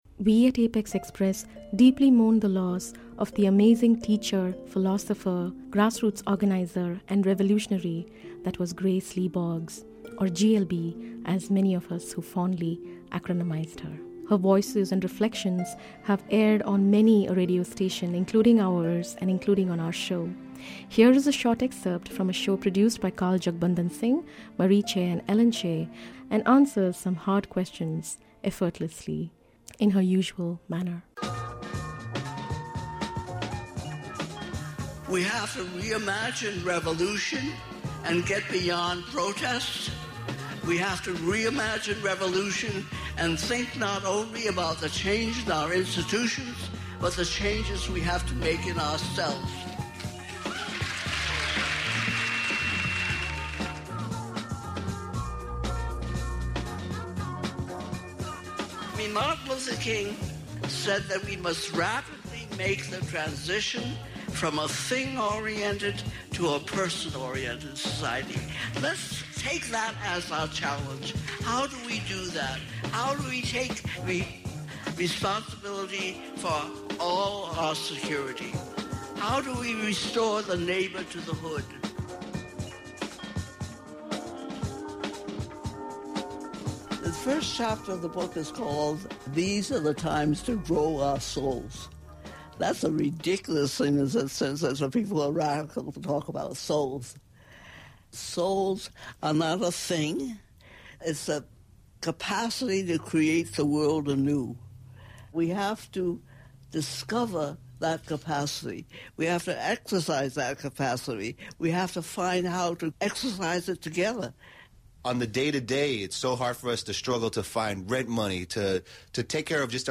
She was a familiar voice on KPFA airwaves, and on our show – inspiring us with her fierce and compassionate wisdom. Her genius was forged from decades of tireless activism in civil rights, anti-racism, labor organizing, environmental justice and feminism.